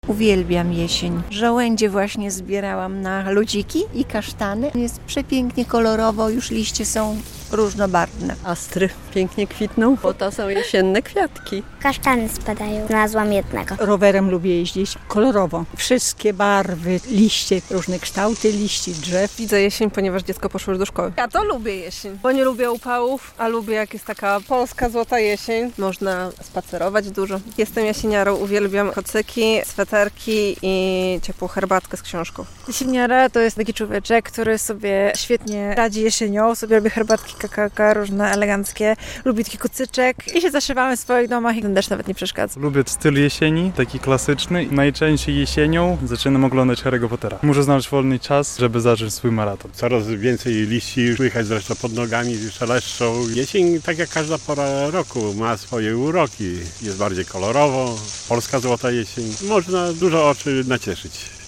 Pierwszy dzień jesieni - relacja